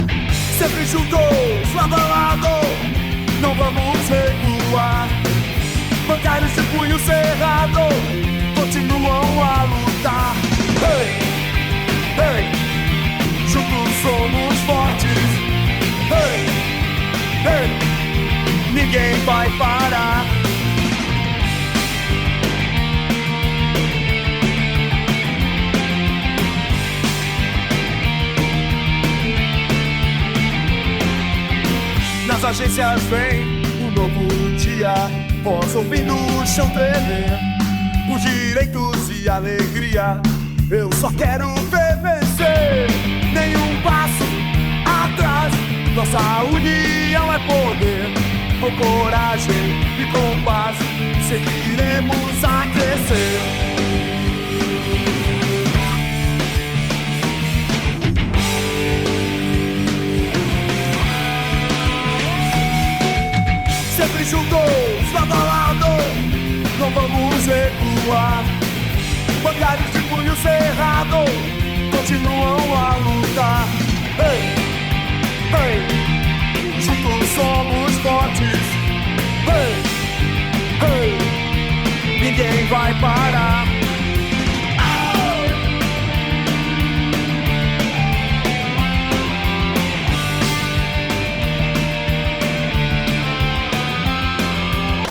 [ "rock" ]